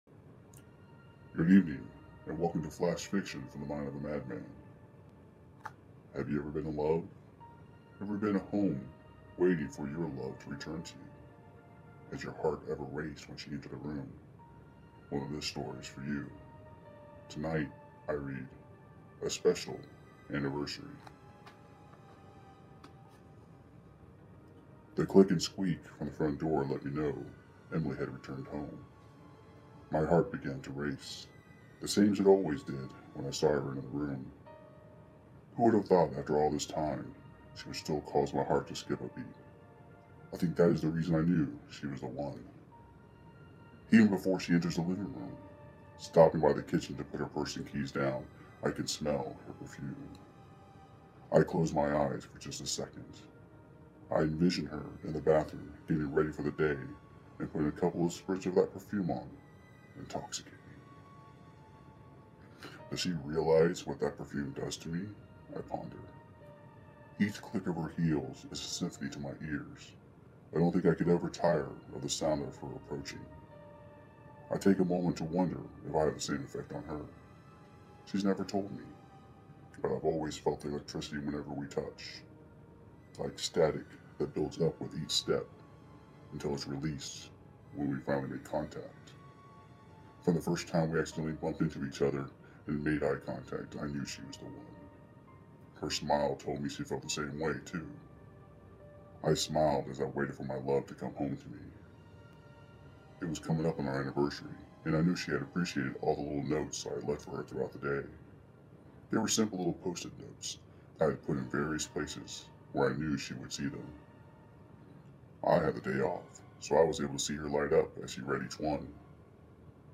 Flash Fiction Reading! Episode 1!